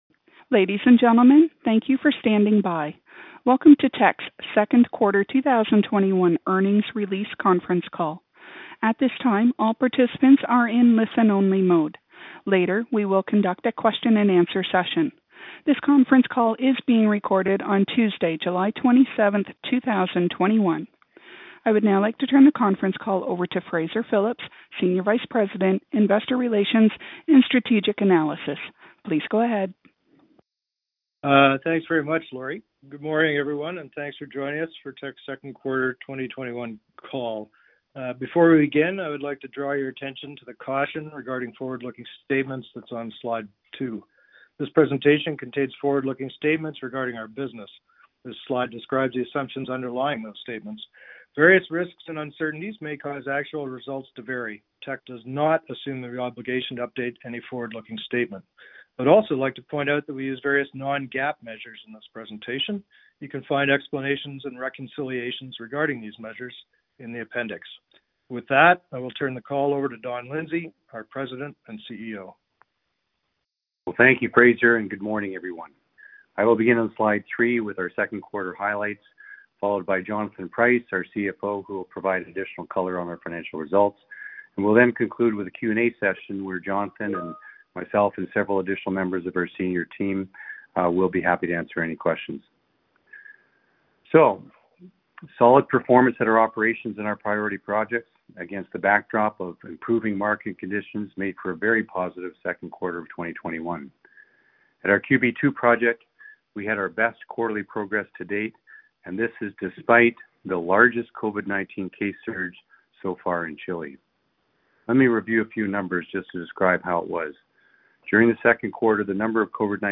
Q2 2021 Conference Call
Teck will host an investor conference call to discuss its second quarter 2021 results on July 27, 2021 at 8am PT / 11am ET.
The recording of the live audio webcast will be available from 10:00 a.m. Pacific time July 27, 2021.